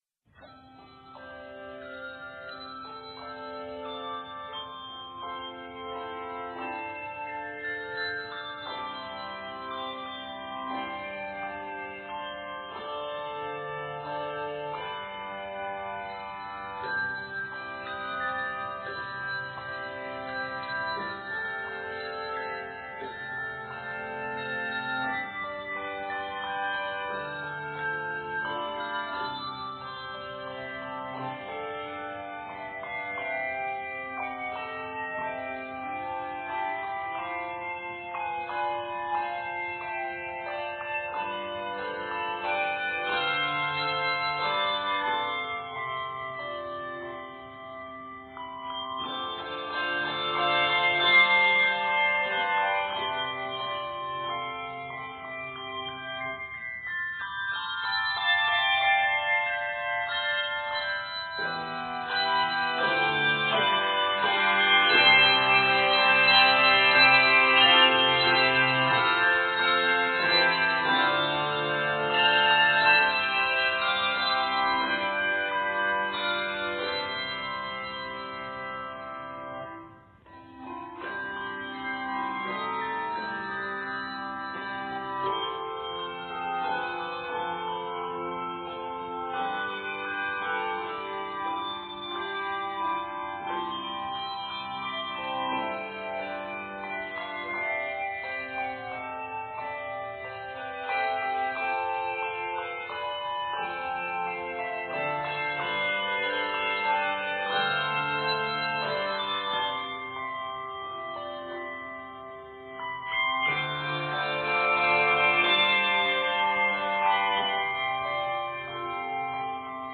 A simple composition using rich harmonies.